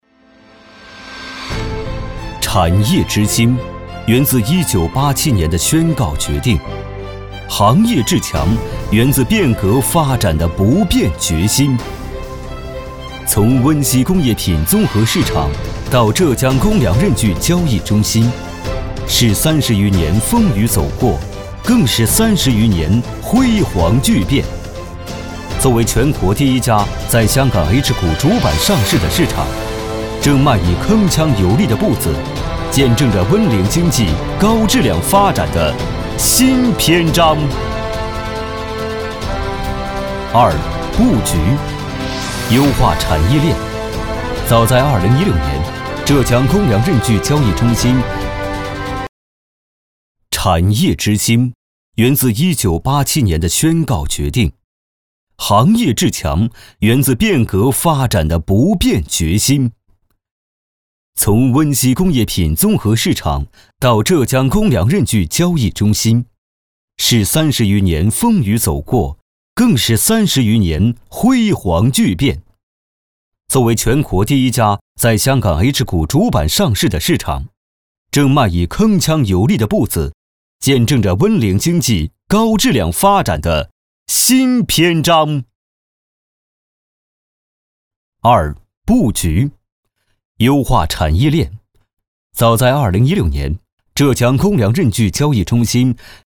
中文男声
• 宣传片
• 大气
• 震撼